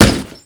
punch1.wav